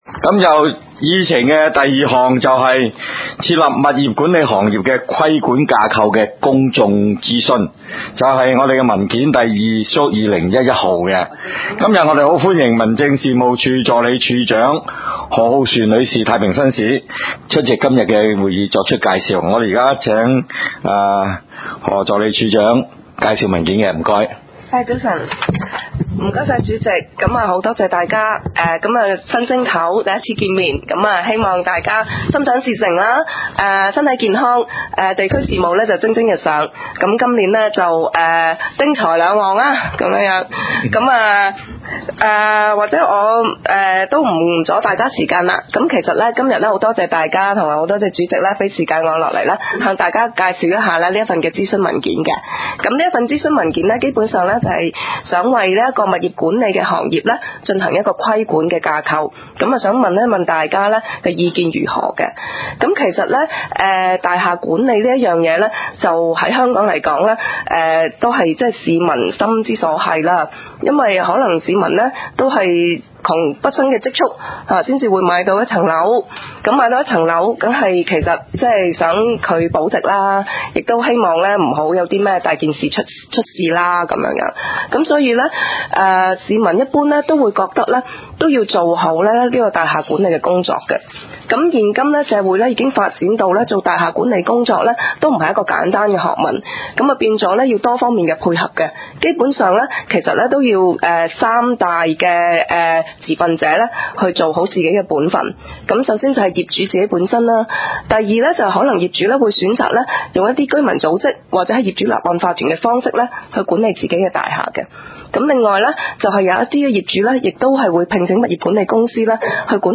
北區區議會2008年第20次會議紀錄